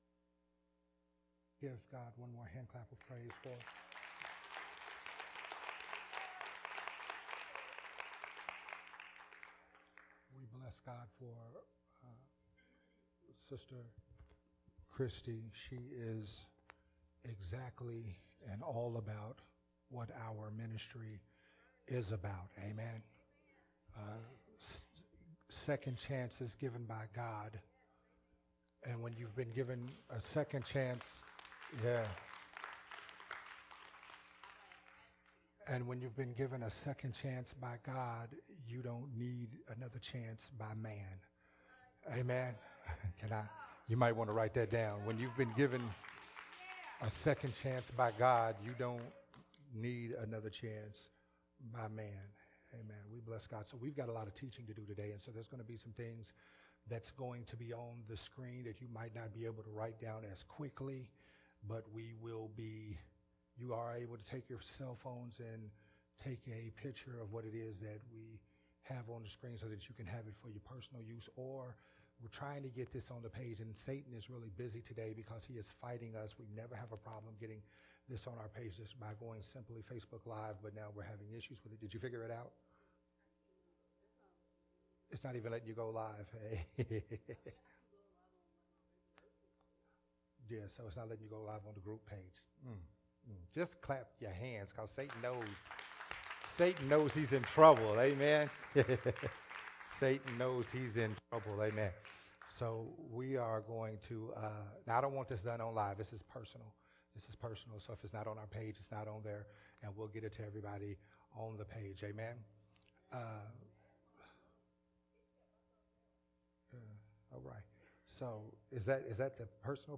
a sermon
recorded at Unity Worship Center